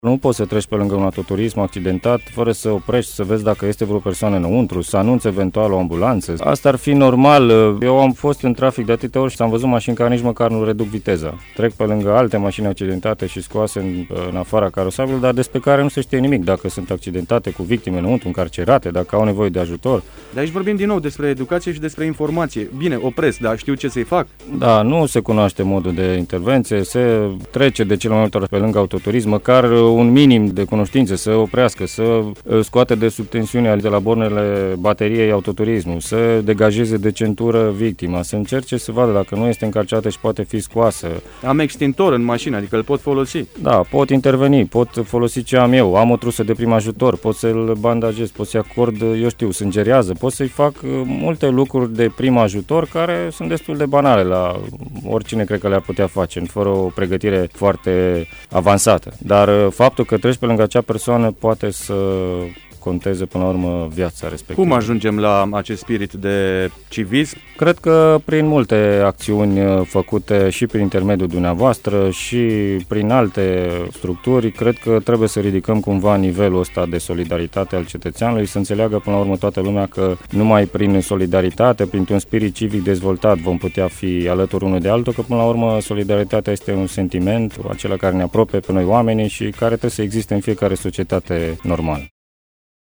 Invitat în programul „Bună dimineața Banat”